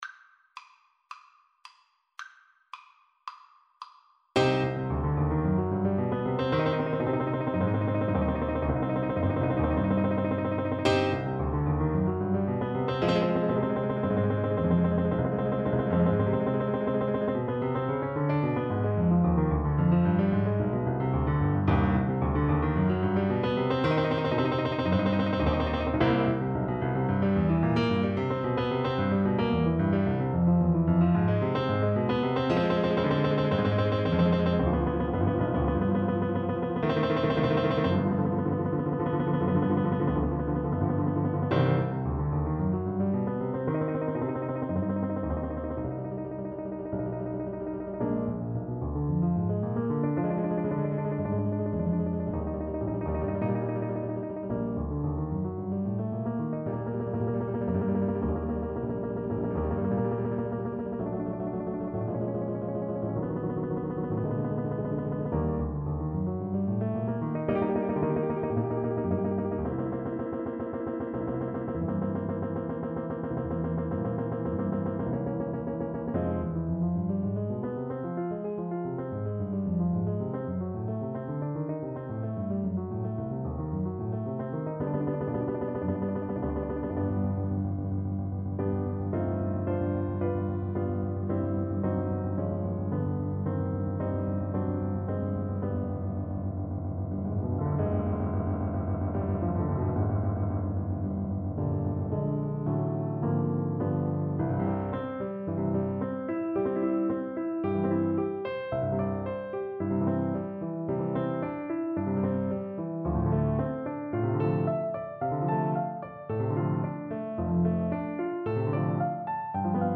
Allegro moderato (=144) (View more music marked Allegro)
Classical (View more Classical French Horn Music)